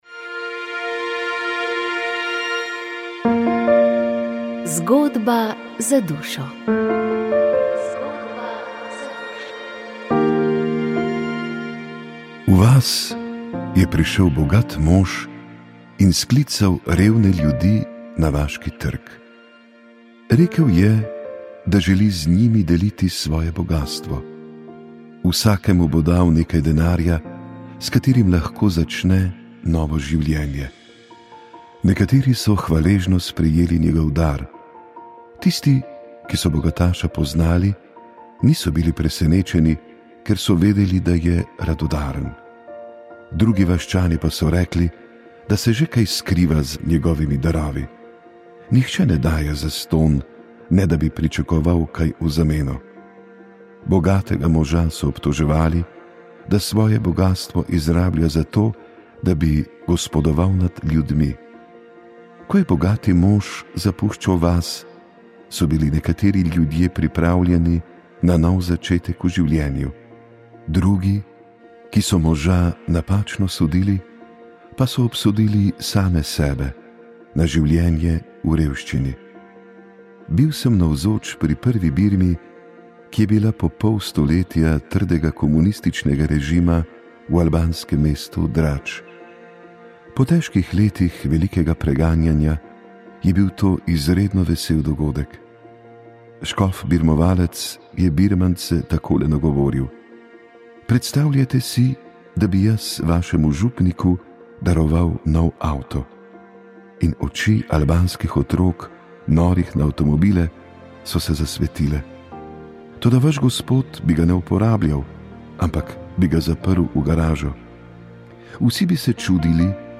pogovor